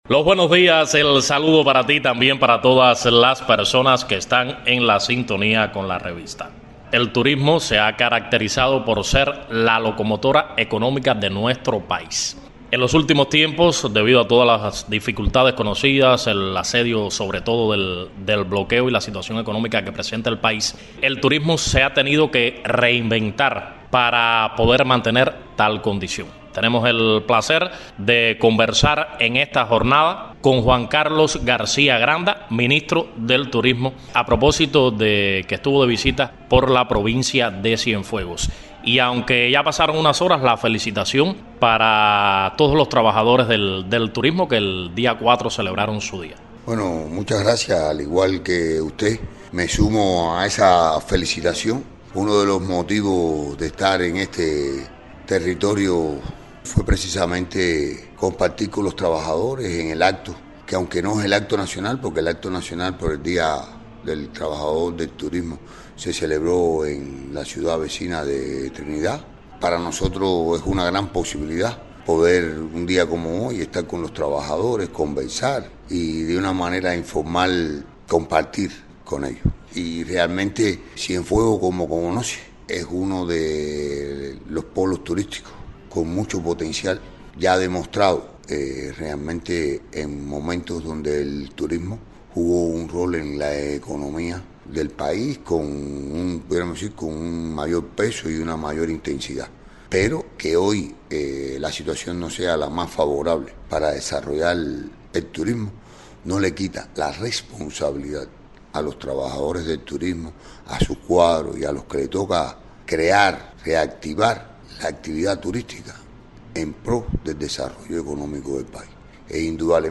El ministro del Turismo Juan Carlos García Granda estuvo de visita en la provincia de Cienfuegos y ofreció valoraciones del trabajo del sector